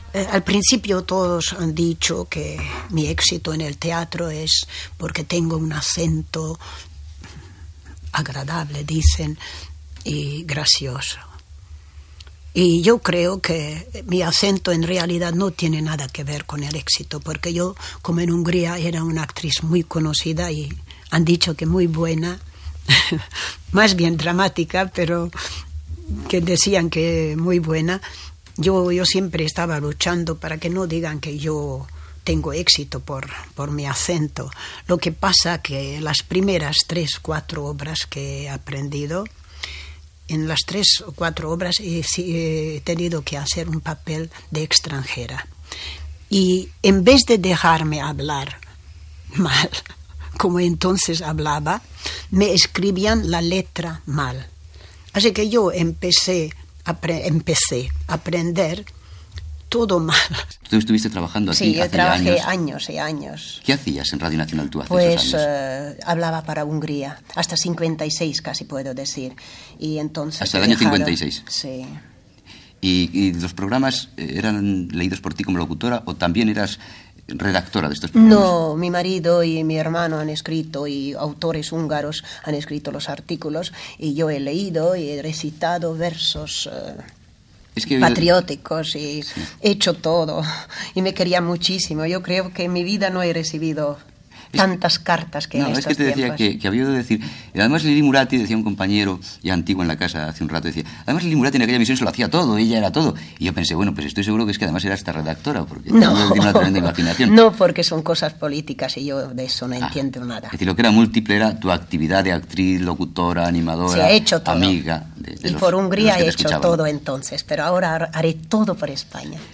Entrevista a l'actriu d'origen hongarés Lili Murati que parla del seu accent i de la seva feina com a locutora dels programes en hongarés de Radio Nacional de España en ona curta
Fragment extret del programa "Audios para recordar" de Radio 5 emès l'11 de maig del 2015.